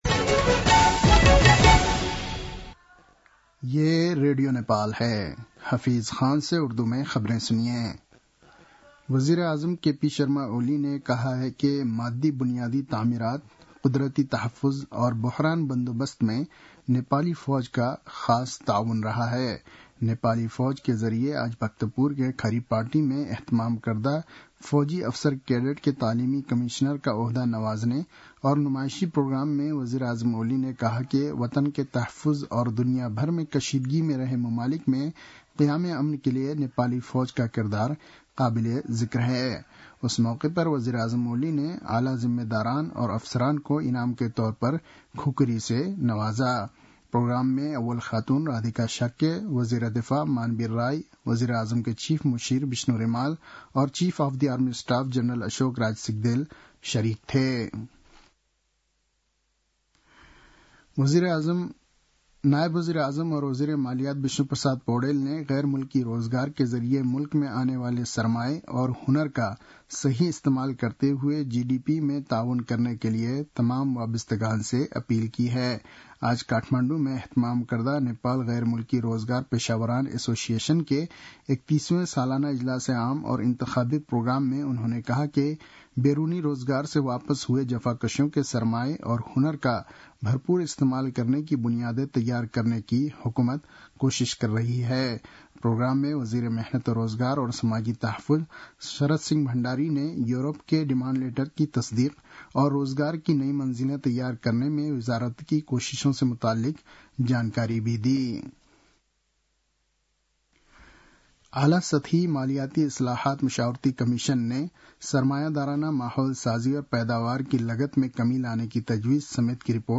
उर्दु भाषामा समाचार : २९ चैत , २०८१
Urdu-news-12-29.mp3